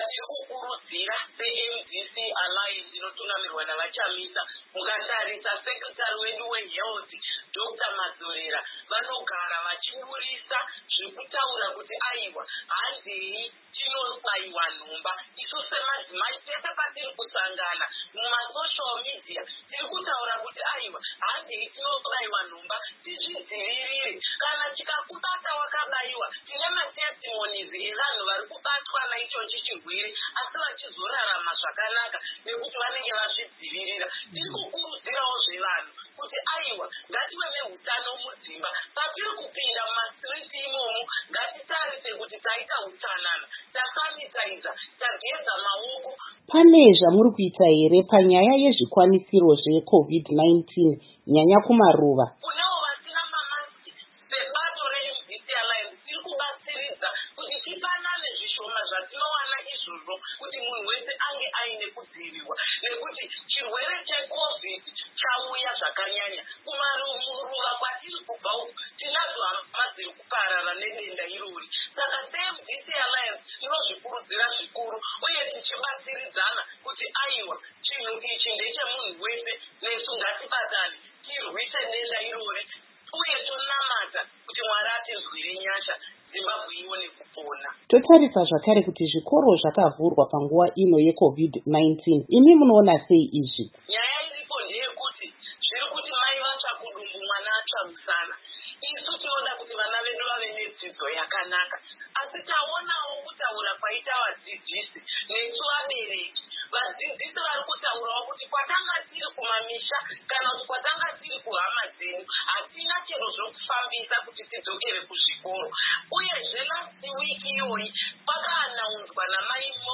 Hurukuro na Amai Lynette Karenye-Kore